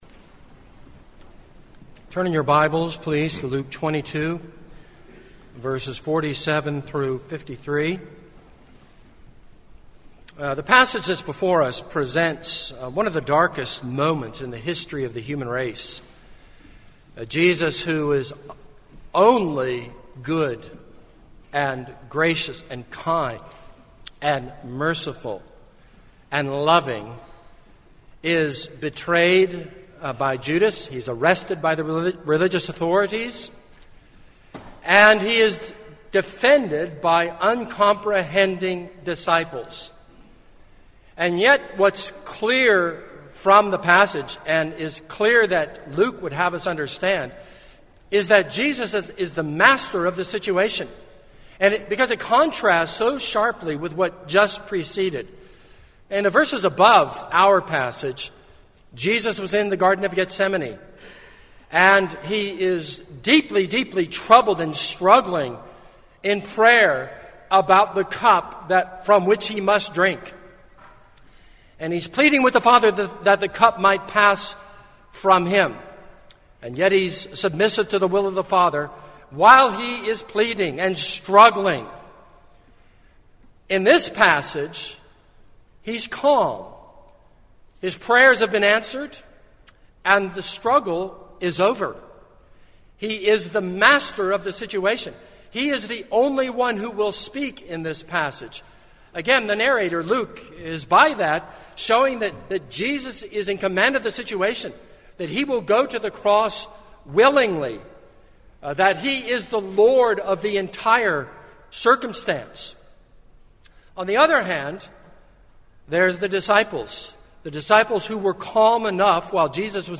This is a sermon on Luke 22:47-53.